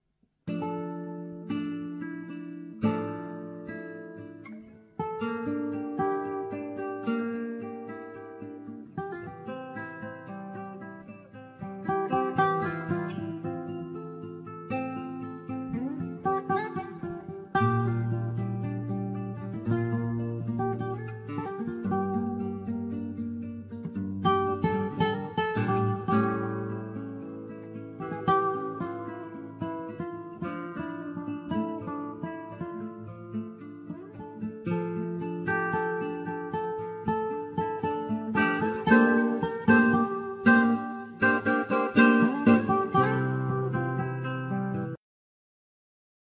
Guitar(Spanish,Acoustic),Banjo,Percussions
Synthsizer,Programming
Guitar(Spanish,Electric),Percussions
Tenor saxophone